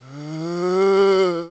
c_zombie_bat2.wav